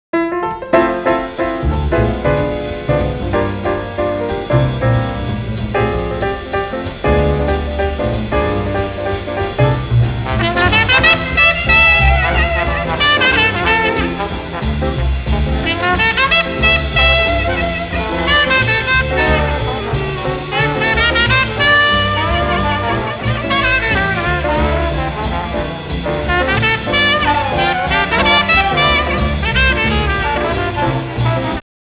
Recorded New York City
trombone
clarinet
piano
bass
drums
tenor sax